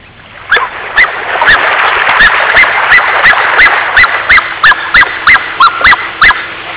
S'anomena cigne mut, tot i que emet uns grunys molt típics i és capaç també de xiular amb molta força.
cigne_so.au